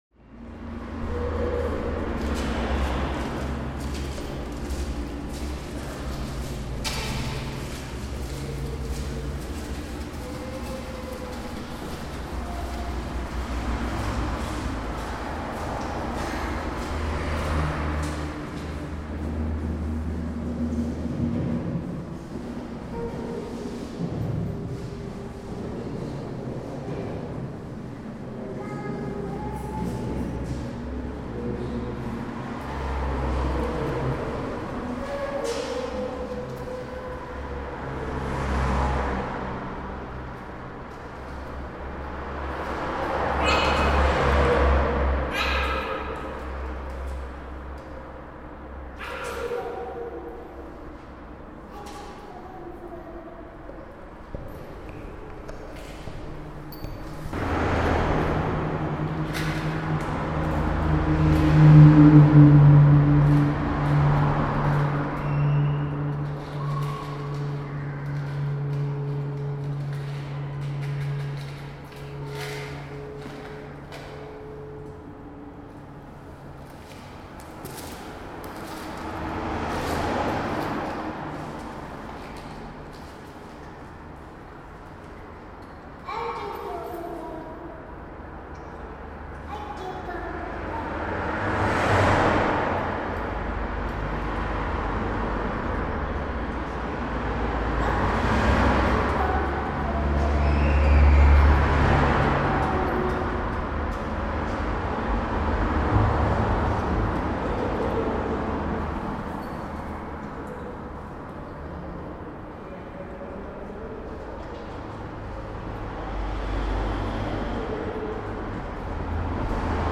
Tagy: doprava exteriér zahrady
Dlouhý průchod se schodištěm z "klidných" Kinského sadů směrem dolů na hlučnou Holečkovou ulici, kde je skoro neustále velký provoz. Chodba se schodištěm funguje jako akustický dalekohled. Zvuk kol a motorů projíždějících aut vystřeluje vzhůru do chodby a jakmile vozidla přejedou, zvuk utichá, odražen a pohlcen silnou zdí.